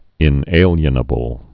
(ĭn-ālyə-nə-bəl, -ālē-ə-)